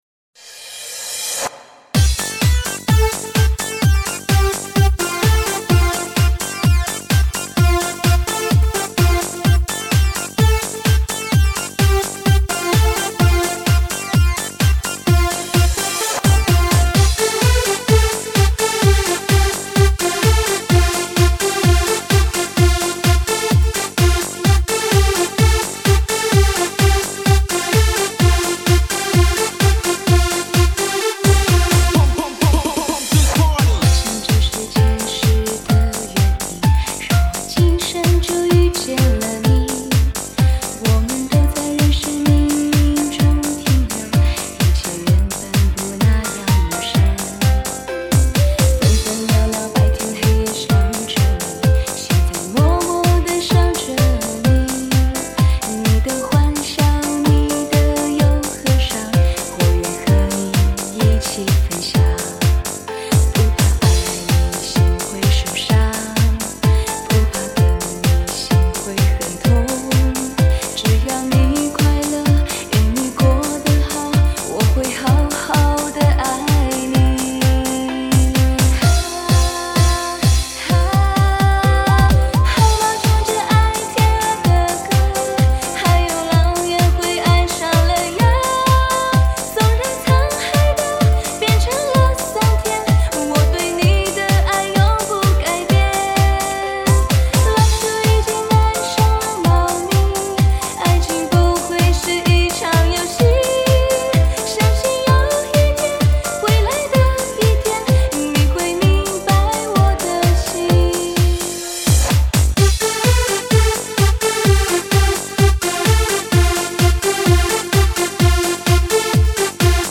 激情的震撼舞曲；气势澎湃无与伦比。
绝美的天籁之声；清脆动人静化心灵。
其音质之纯美 动态对比之鲜明和立体感之美